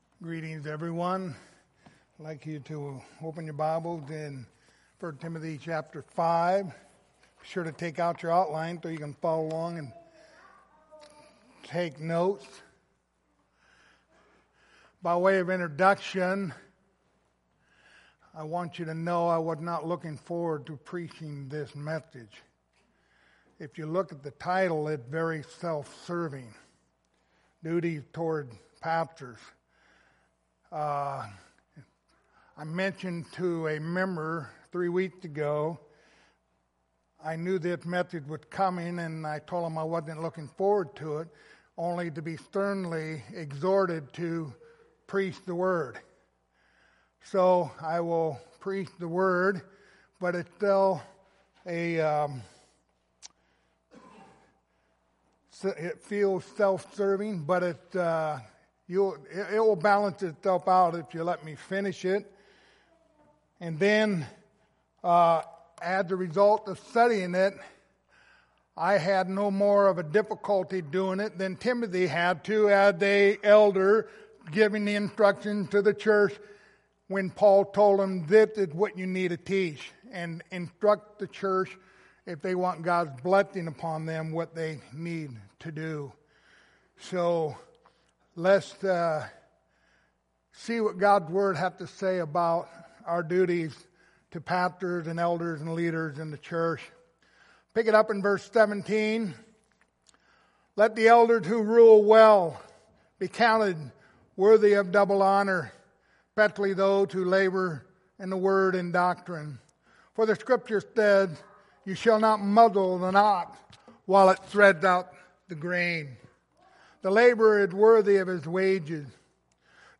Passage: 1 Timothy 5:17-25 Service Type: Sunday Morning